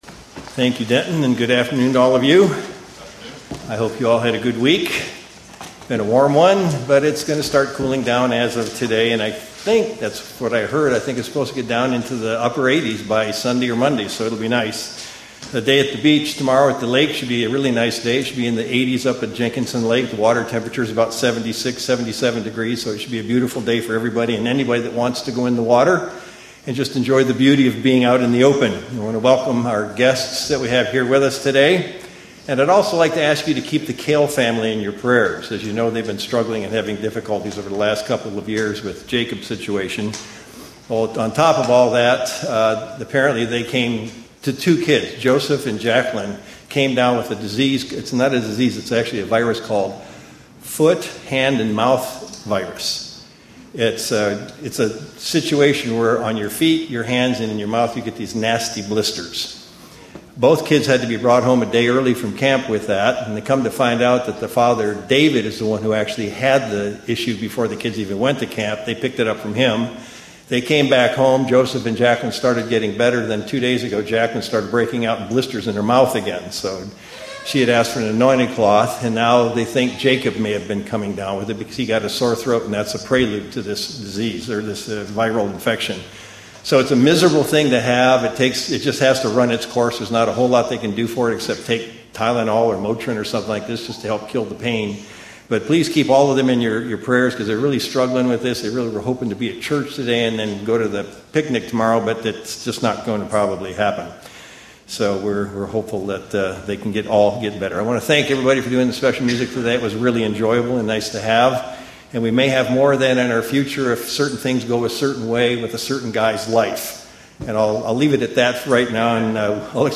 View on YouTube UCG Sermon Studying the bible?